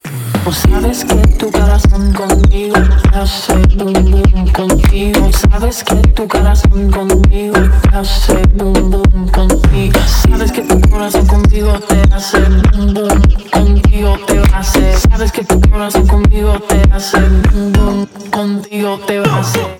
• Качество: 128, Stereo
позитивные
женский вокал
заводные
клубняк
латиноамериканские
Latin Pop